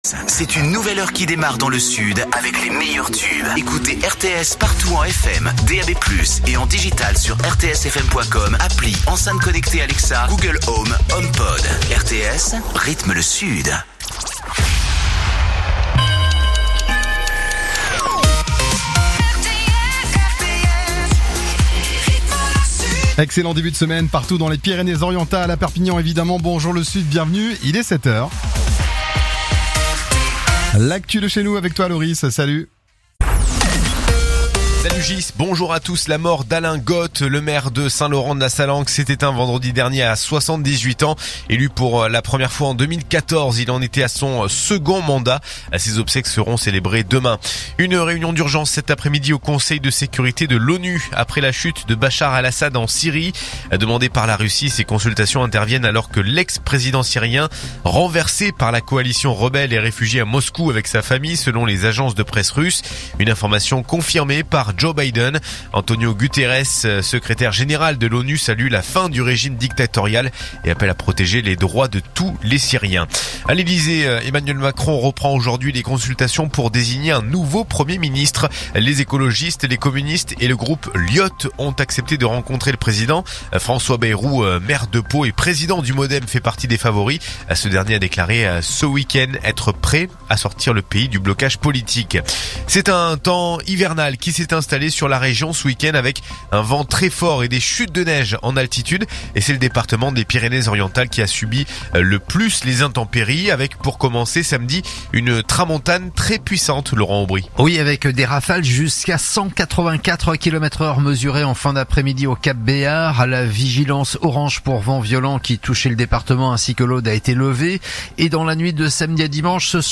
info_perpignan_227.mp3